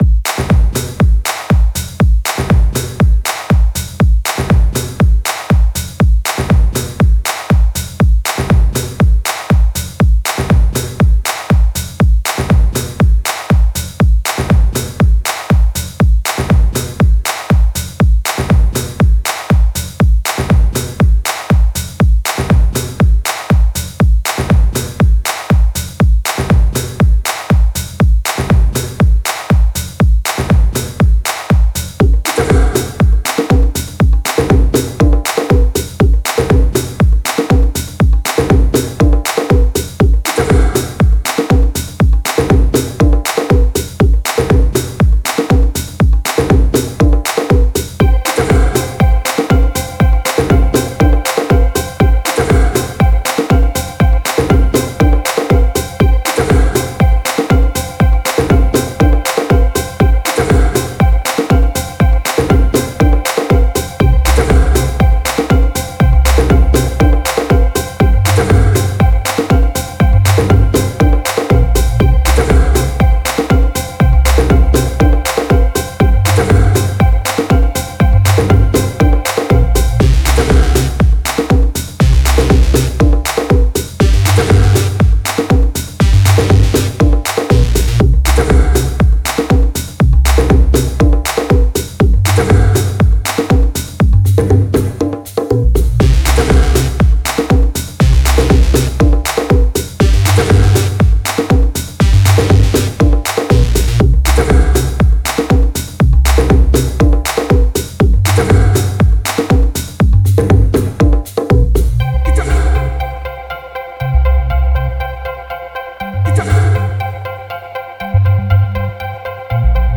Mastering Samples